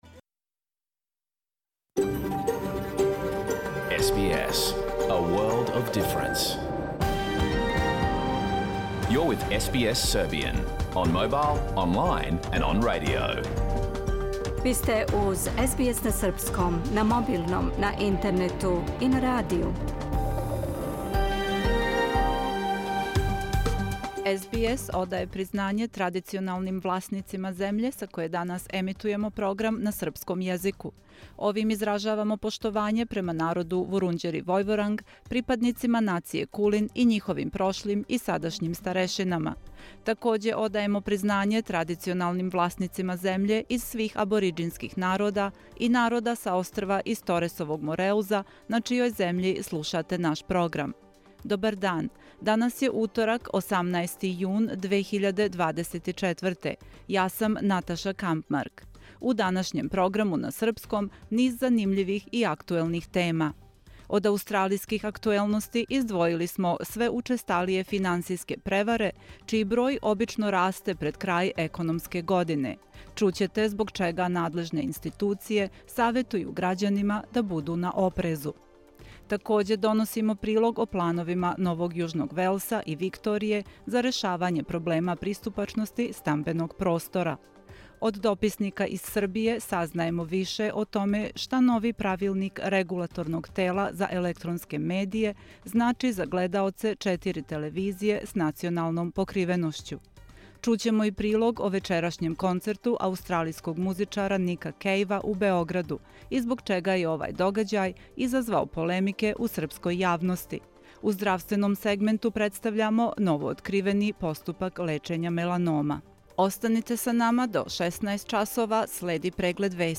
Програм емитован уживо 18. јуна 2024. године
Уколико сте пропустили данашњу емисију, можете је послушати у целини као подкаст, без реклама.